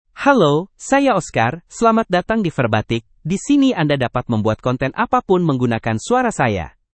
MaleIndonesian (Indonesia)
OscarMale Indonesian AI voice
Oscar is a male AI voice for Indonesian (Indonesia).
Voice sample
Listen to Oscar's male Indonesian voice.